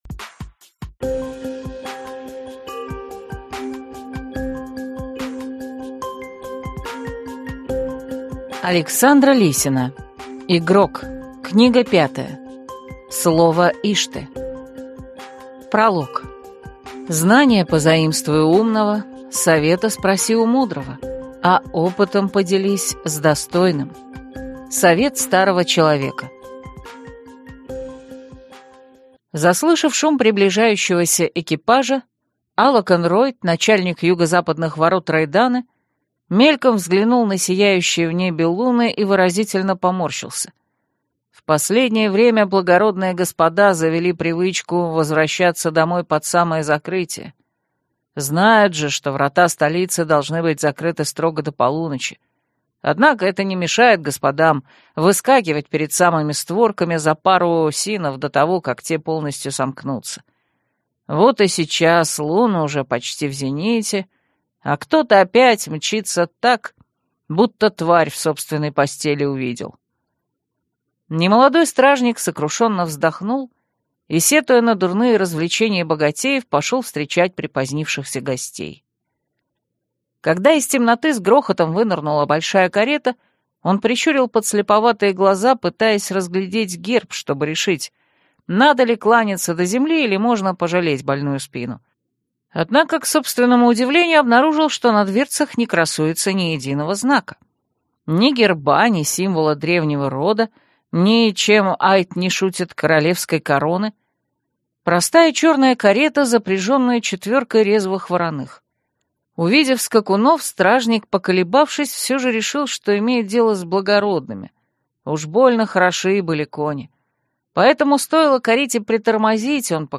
Аудиокнига Слово Ишты | Библиотека аудиокниг
Прослушать и бесплатно скачать фрагмент аудиокниги